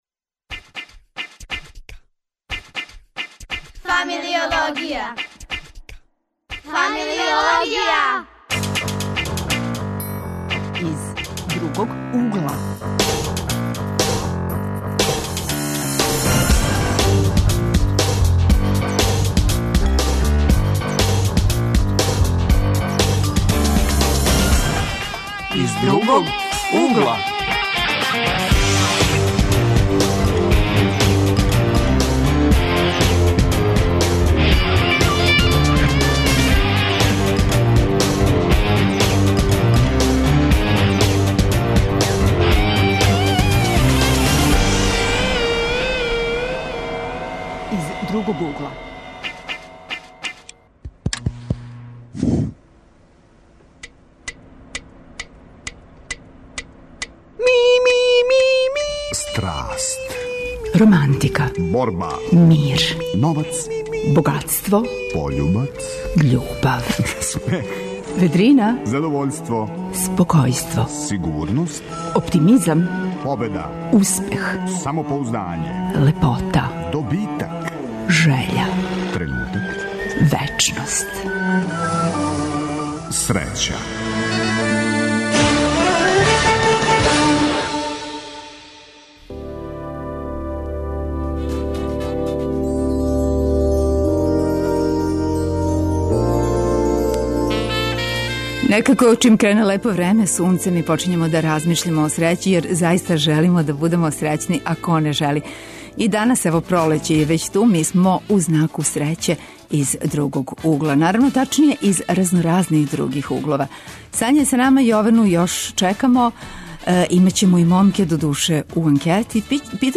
Питамо се шта нам је све потребно да бисмо рекли да смо срећни. Гости су средњошколци и студенти, млади амбициозни људи.